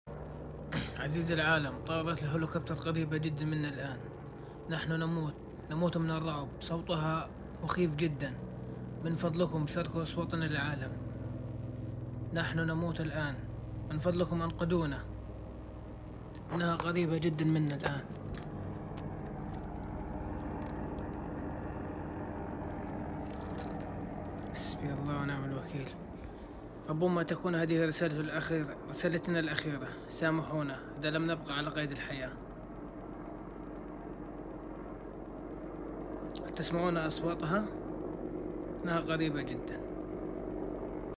Helicopters are extremely close to sound effects free download
Their sound is terrifying beyond words.